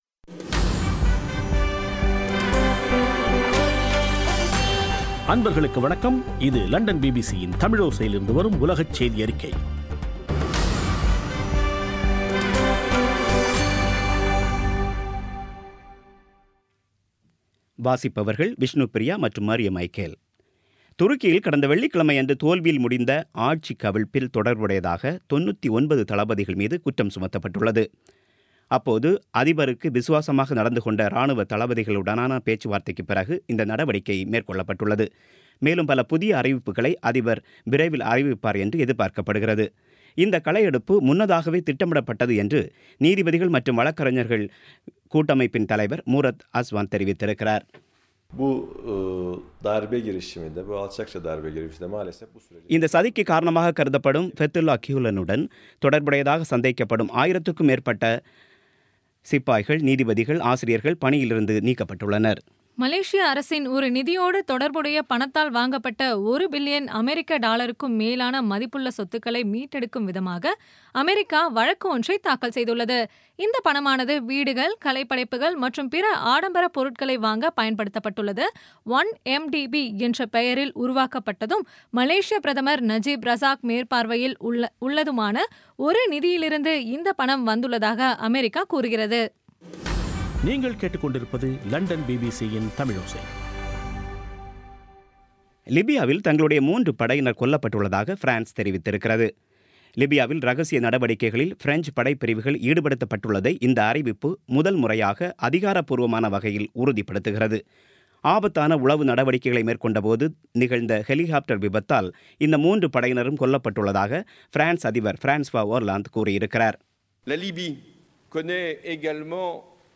பி பி சி தமிழோசை செய்தியறிக்கை (20/07/16)